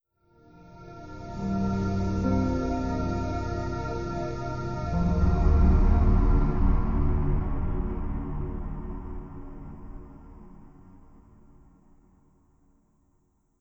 Windows NT 10.0 Shutdown.wav